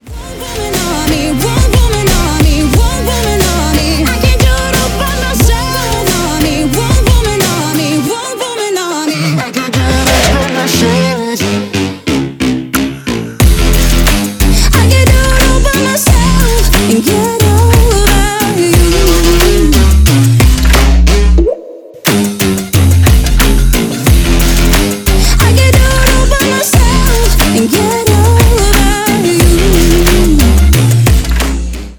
Ремикс # Поп Музыка
громкие